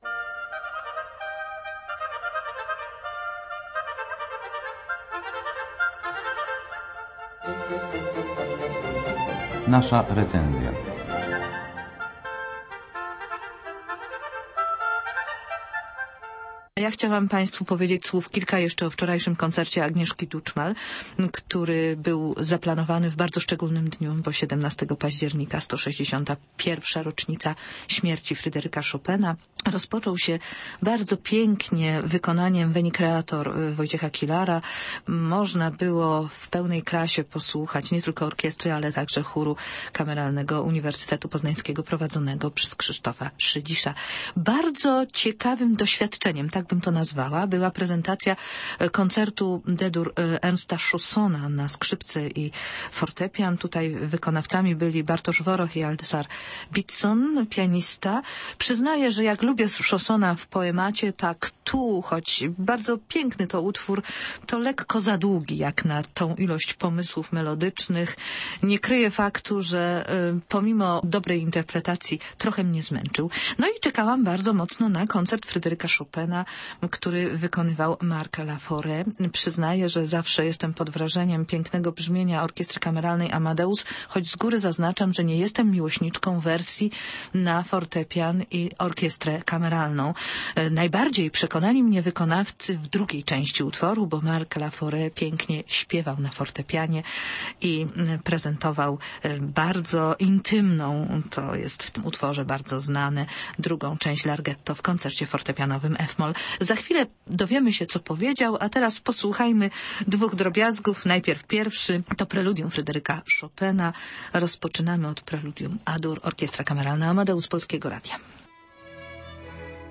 rozmawia wykonawcą tego utworu, francuskim pianistą Markiem Laforetem